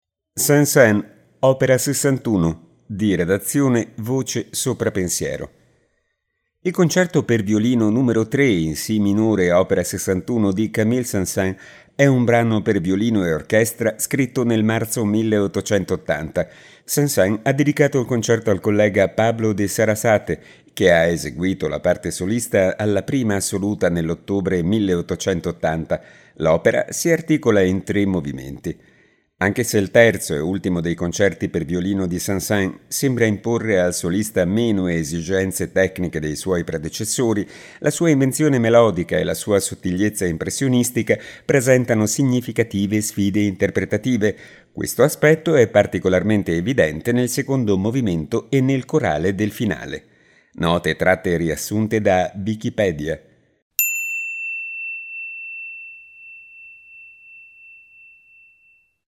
Il Concerto per violino n° 3 in si minore op. 61 di Camille Saint-Saëns è un brano per violino e orchestra scritto nel marzo 1880.